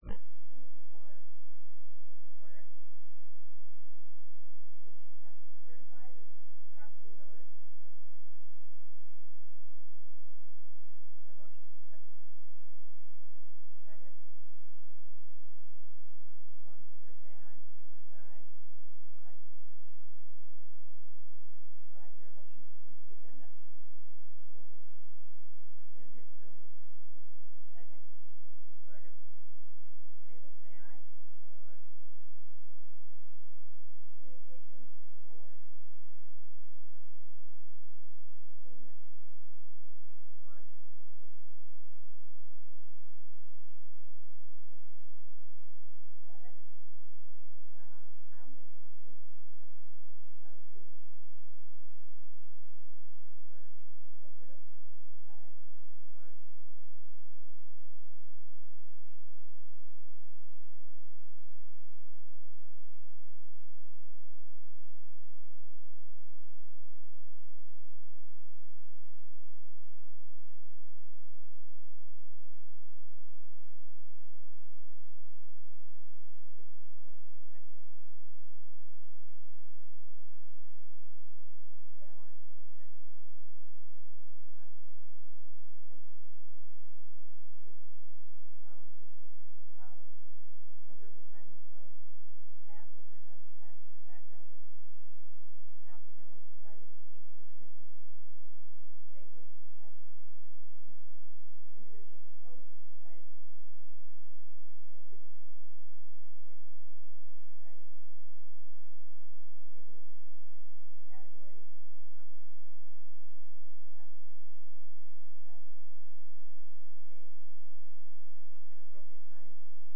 Meeting Information Agenda Minutes Agenda (26 KB) NOTICE OF MEETING SAUK COUNTY BOARD OF ADJUSTMENT DATE: February 23, 2012 TIME: 9:00 a.m. PLACE: Board Room, Sauk County West Square Building 505 Broadway Baraboo, WI. 53913 ORDER OF BUSINESS 1.
Board of Adjustment public hearing beginning at 9:00 a.m. A. The Kraemer Company.